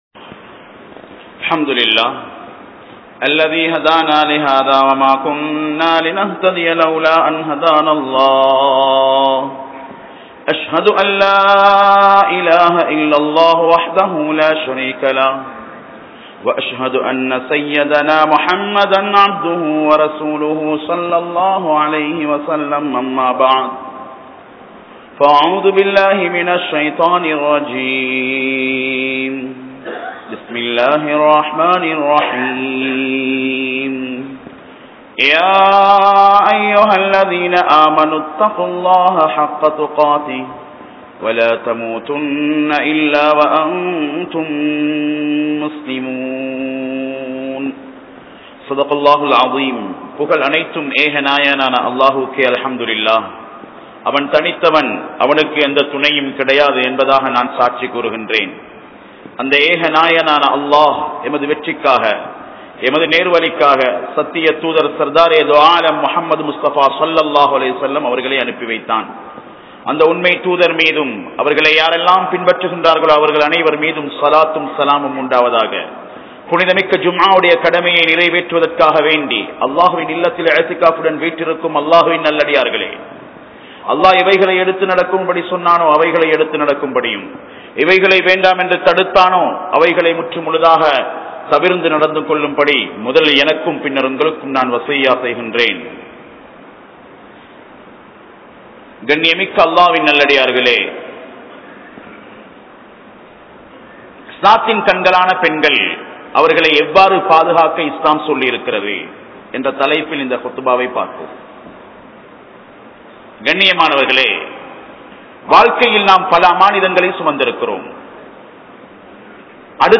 Kanampittya Masjithun Noor Jumua Masjith